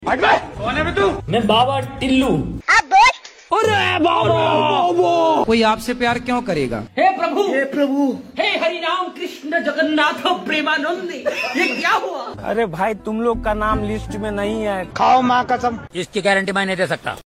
viral memes sounds effects sound effects free download